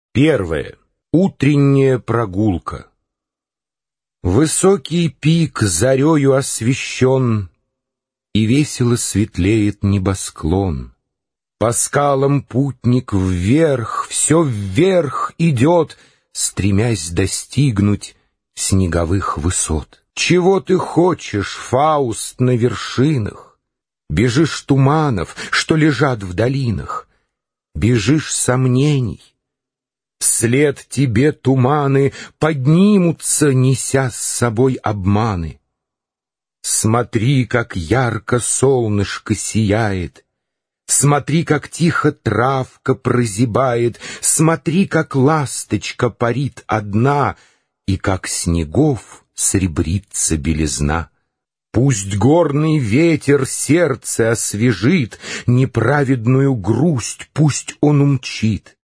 Аудиокнига Фауст | Библиотека аудиокниг